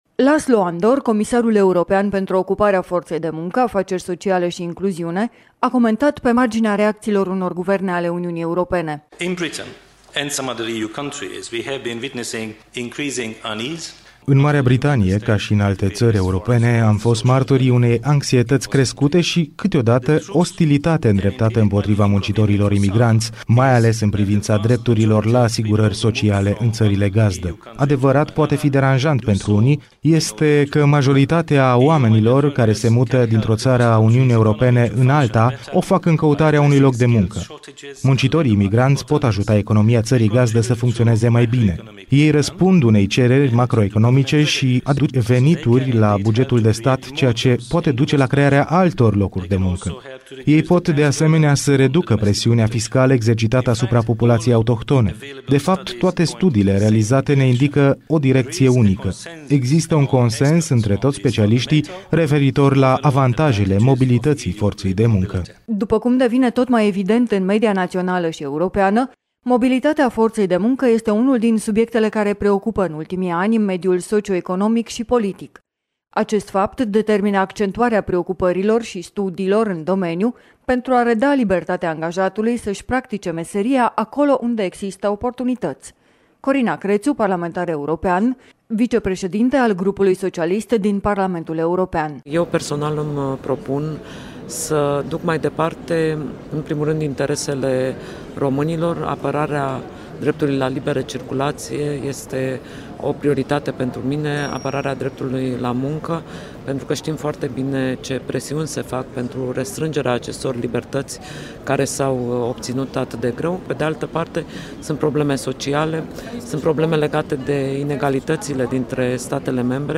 Laszlo Andor, comisarul european pentru ocuparea fortei de munca, afaceri sociale si incluziune a comentat pe marginea reacţiilor unor guverne ale Uniunii Europene: